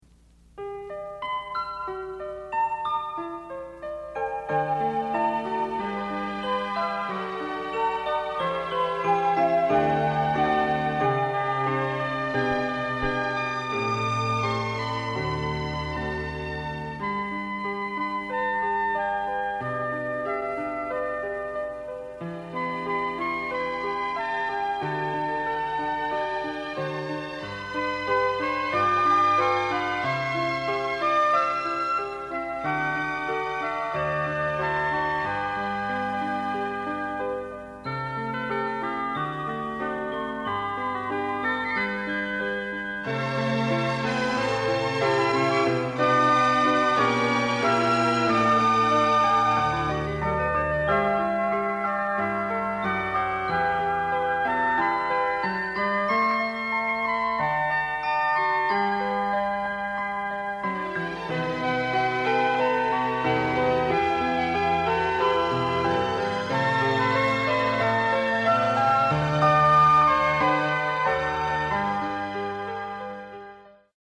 防災行政無線から流れるチャイム音は、機器の日常点検のため、毎日午後4時45分に放送しています。